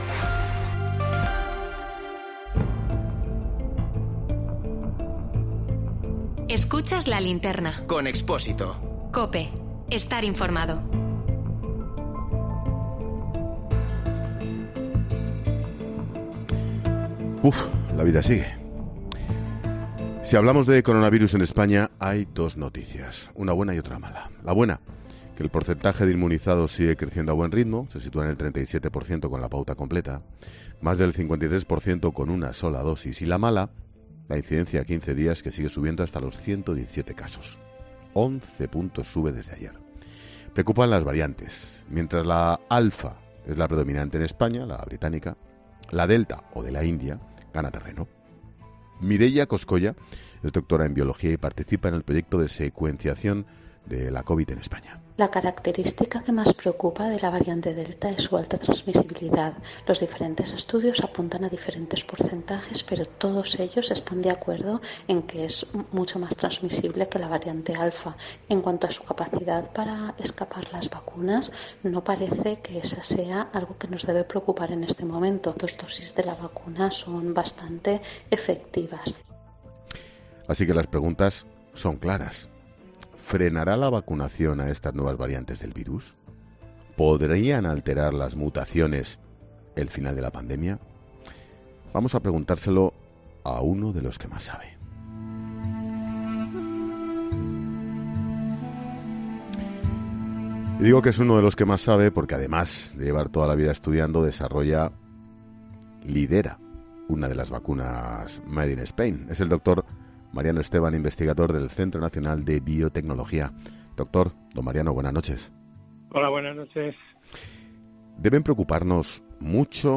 En nuestro tema del día, analizamos con expertos las nuevas variantes del coronavirus y si pueden suponer un peligro para la vacunación